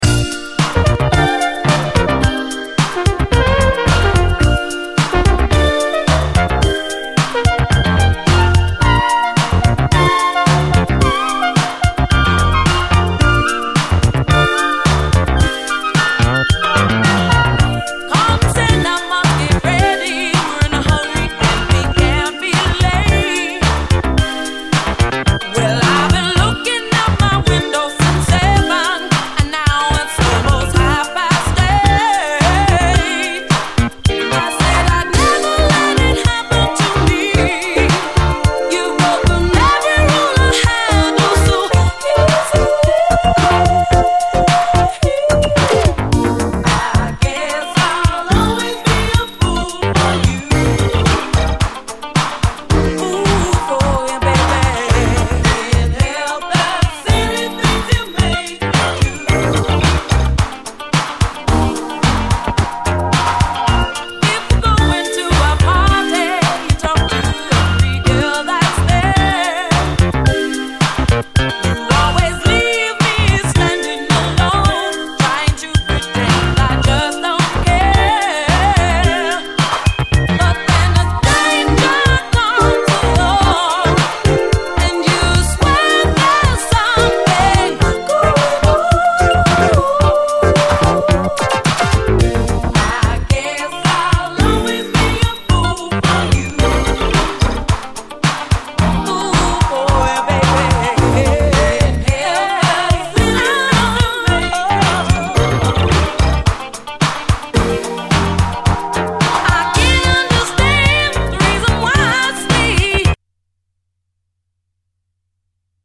SOUL, 70's～ SOUL, DISCO
UK産80’Sエレクトリック・モダン・ソウル！
涼しげなシンセ使いが心地よいライト・ソウル！